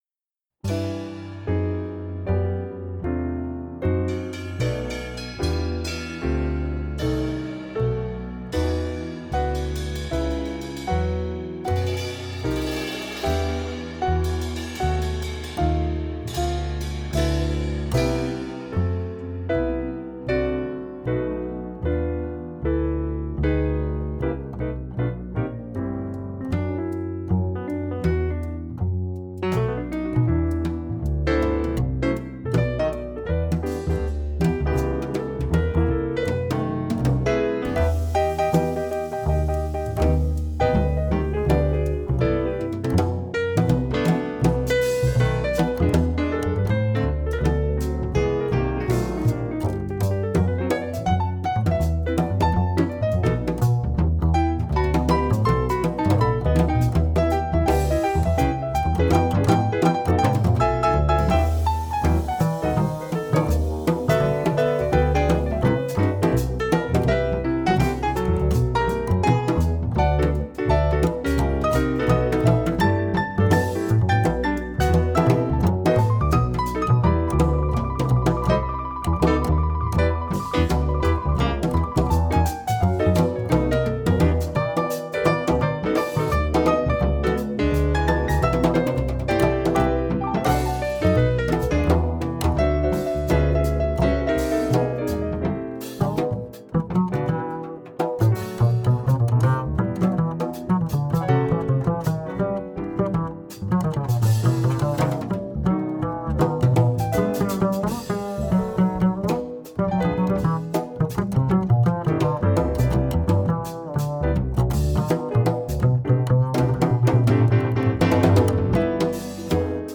It will be a four-camera live production shoot.